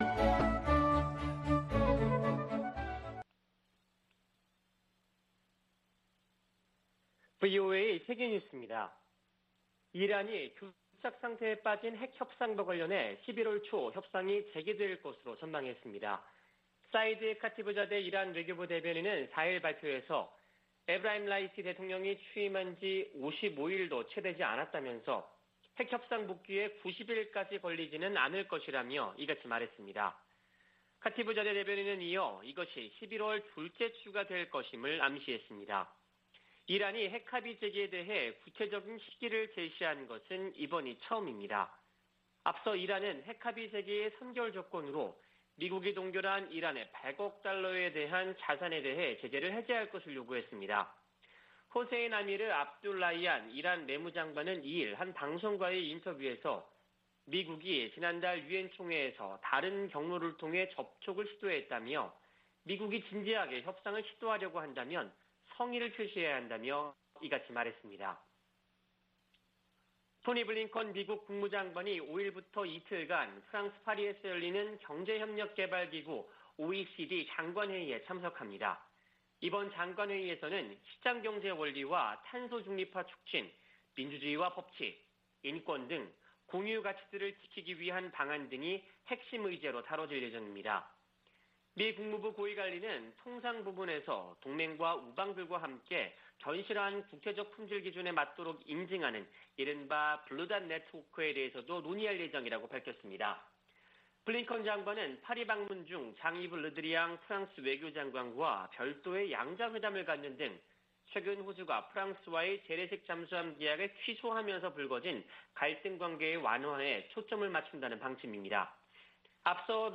VOA 한국어 아침 뉴스 프로그램 '워싱턴 뉴스 광장' 2021년 10월 5일 방송입니다. 북한이 일방적으로 단절했던 남북 통신연락선을 복원했습니다. 유엔 안보리 비공개 회의에서 북한의 최근 '극초음속’ 미사일 발사에 관해 논의했습니다. 미국은 북한과의 대화와 관련해 구체적인 제안을 했지만 답변을 받지 못했다고 밝혔습니다.